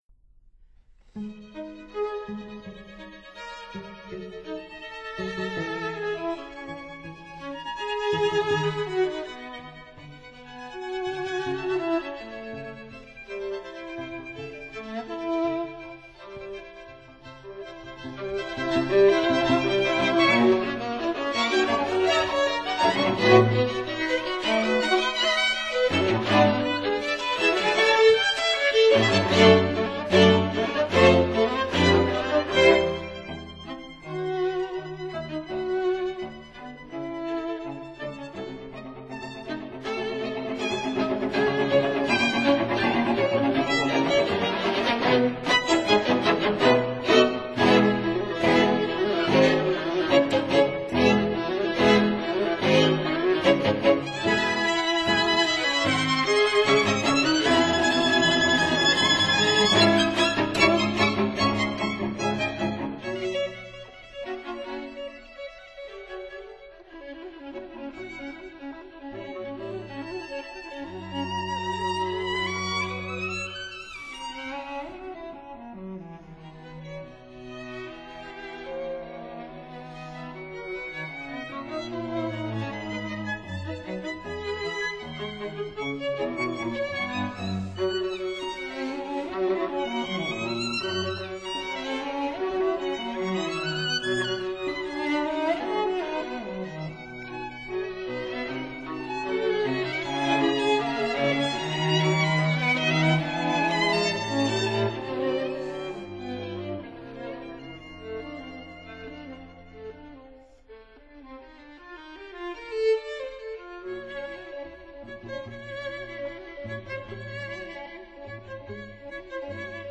•(01) String Quartet No. 8 in E major, Op. 88
•(05) String Quartet No. 10 in E flat major, Op. 51
violins
viola
cello